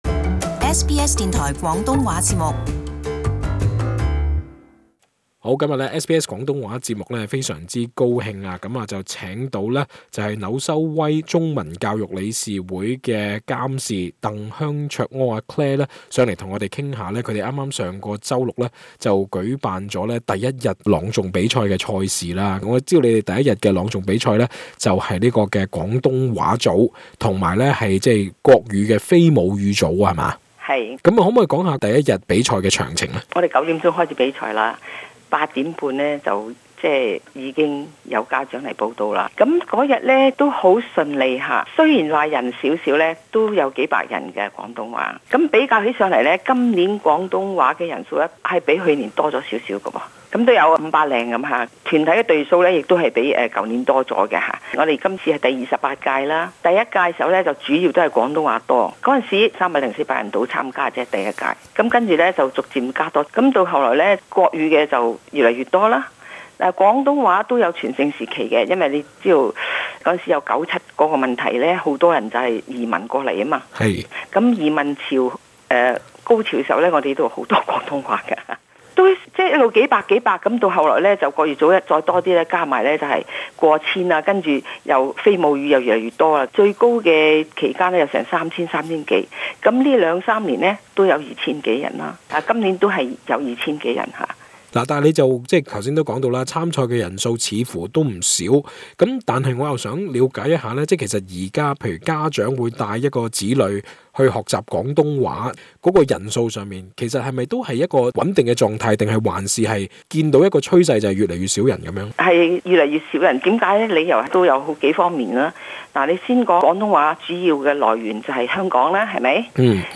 【社區專訪】本地學粵語學生人數是否遞減？